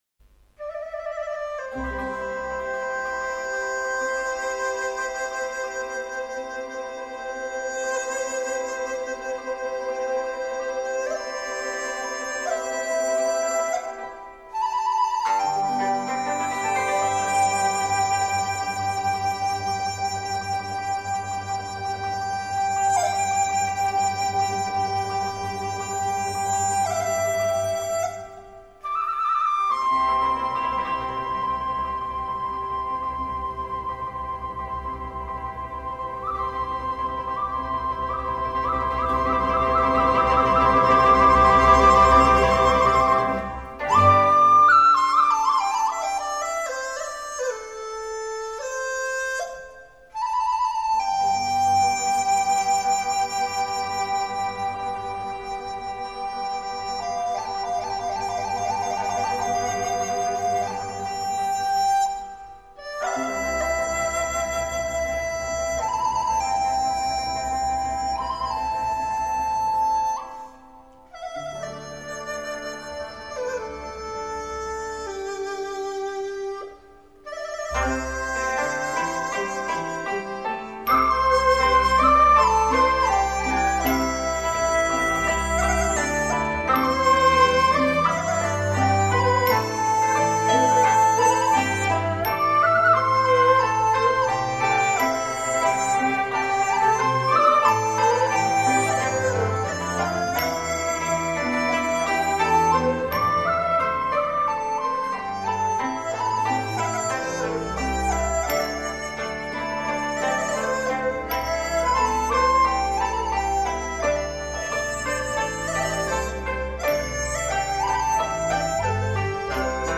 [2005-9-5][分享]一首婉转的江南丝竹，推荐给大家！